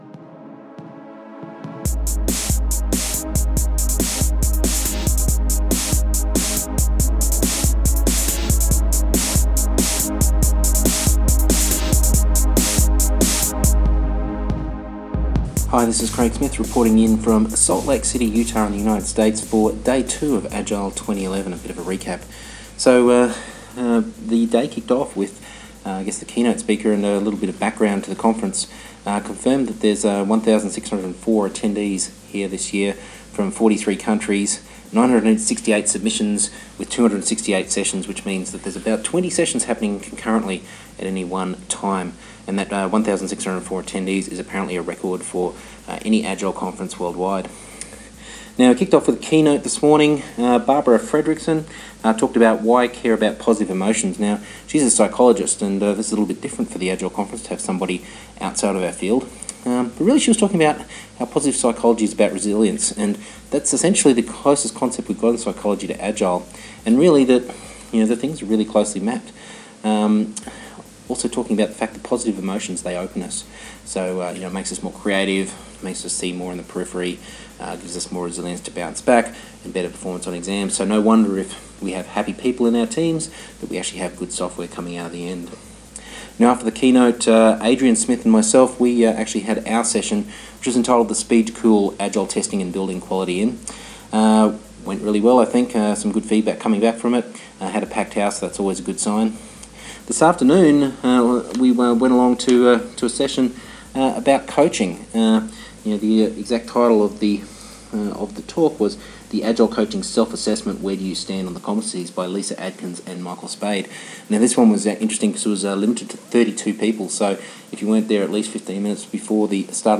direct from the Agile 2011 Conference at Salt Lake City.